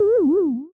sfx_embarrass.ogg